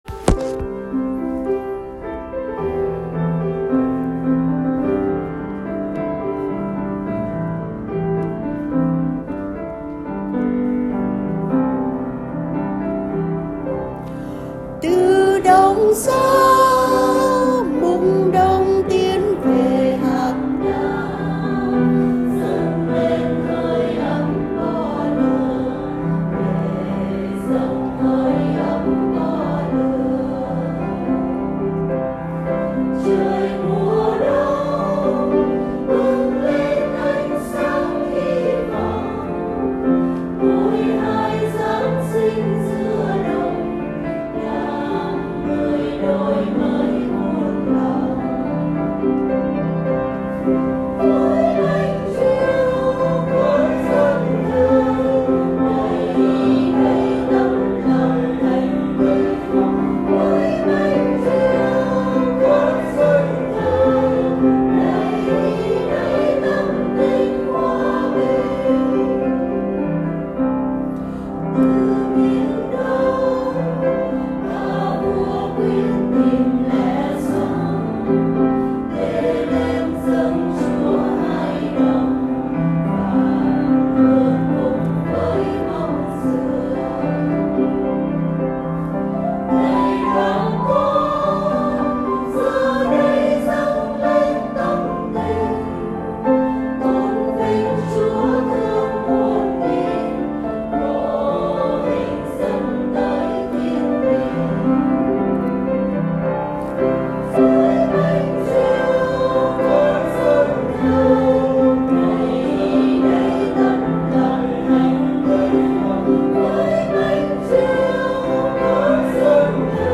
TamTinhHoaBinh_TapHat.m4a